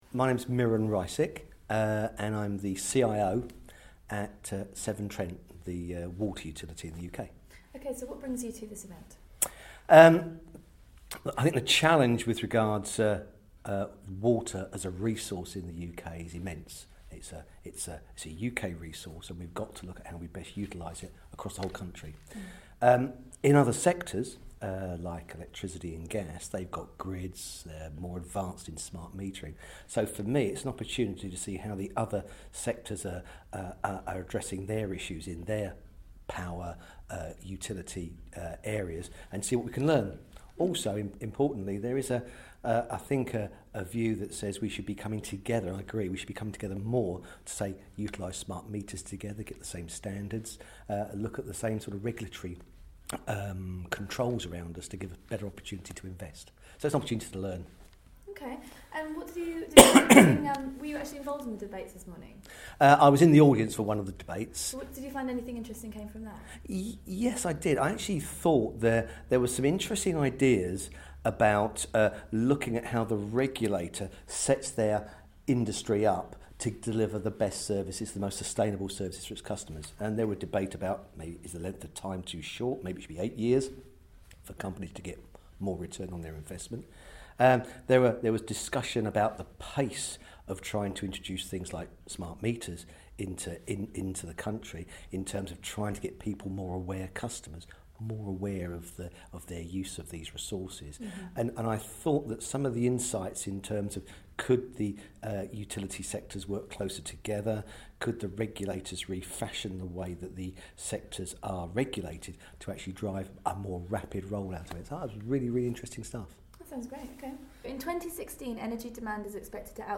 IBM Start Day 2